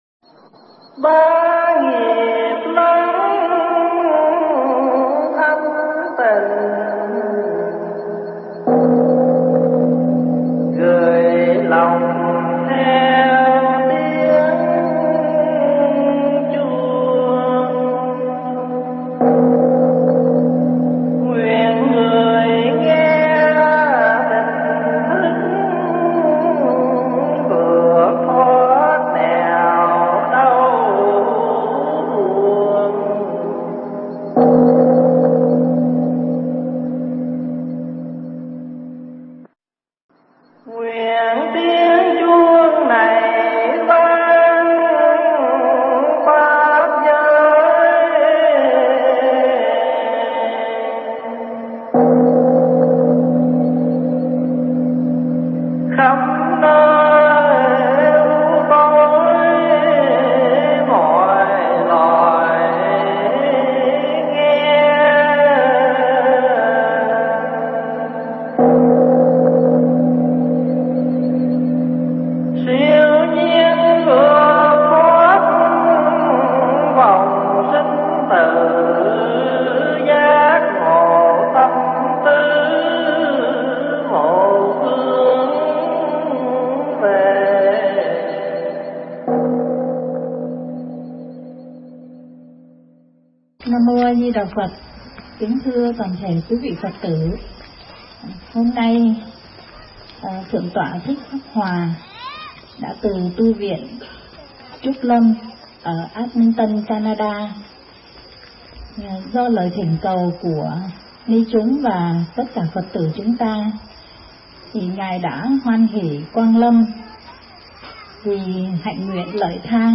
Tải mp3 Thuyết Giảng Tạp Thoại
thuyết giảng tại Chùa Linh Quang, Philadelphia